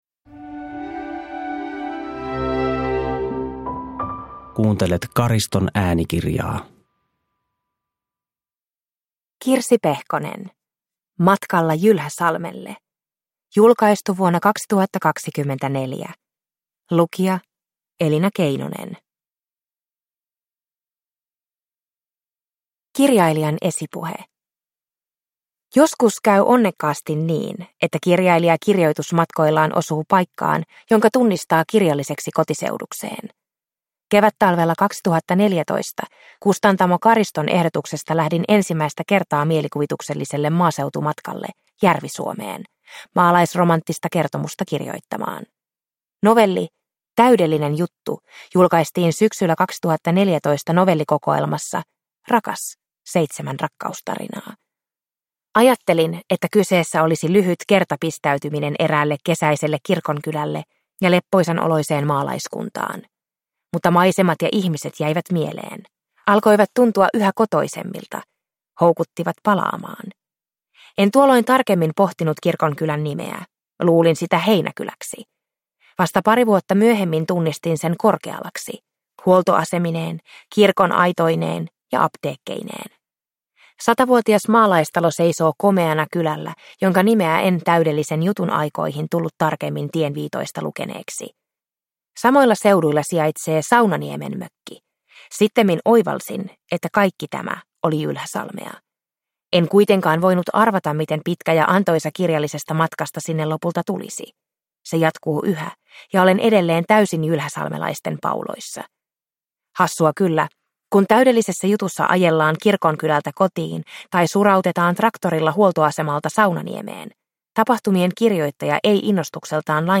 Matkalla Jylhäsalmelle (ljudbok) av Kirsi Pehkonen